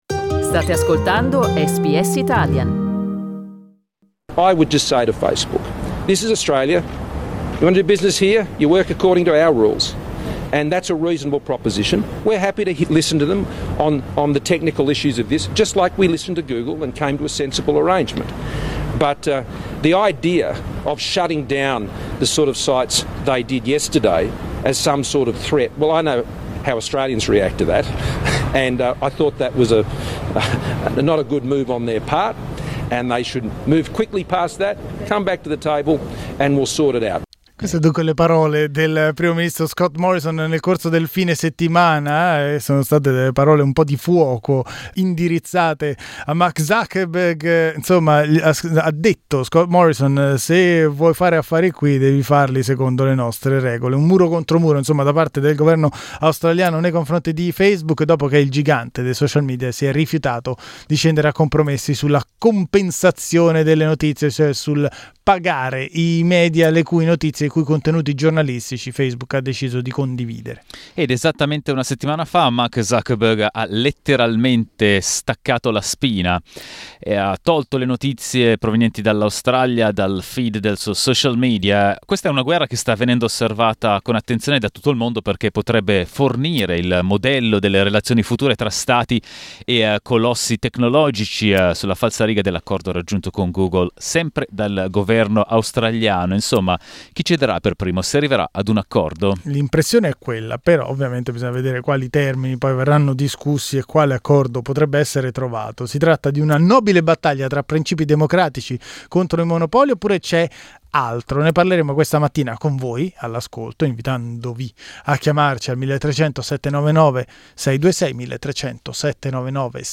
Lo abbiamo chiesto alle nostre ascoltatrici e ai nostri ascoltatori.